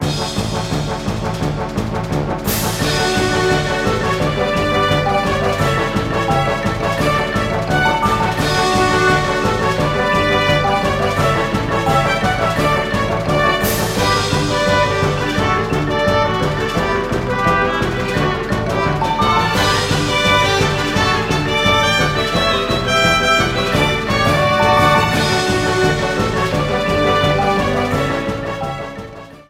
Ripped from the game
clipped to 30 seconds and applied fade-out
Fair use music sample